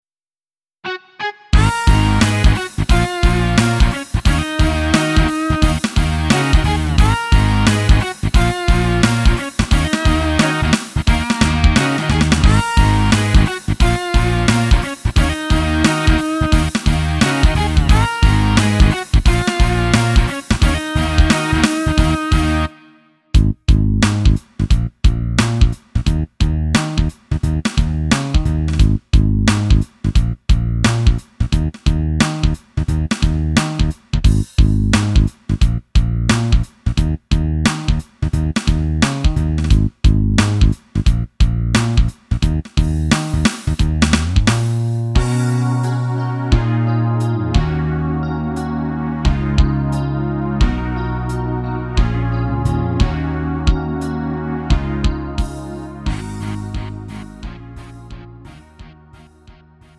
음정 원키 6:18
장르 가요 구분 Lite MR